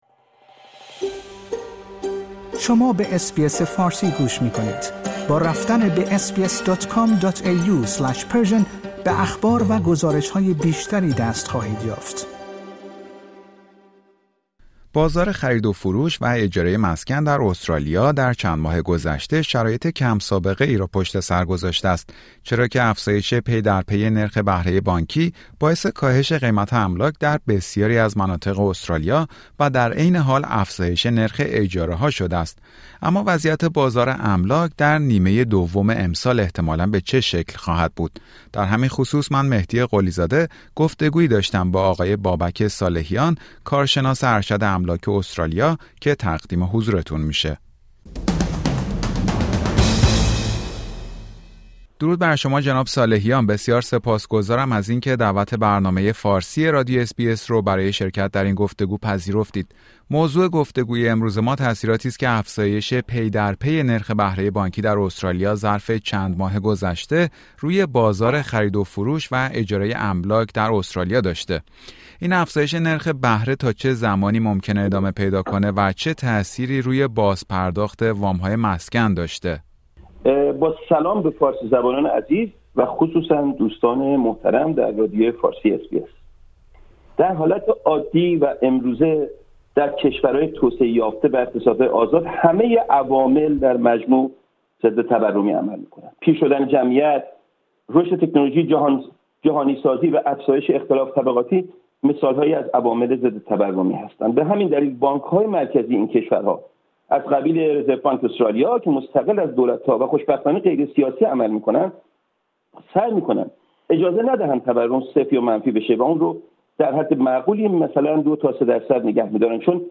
در همین خصوص برنامه فارسی رادیو اس بی اس گفتگویی داشته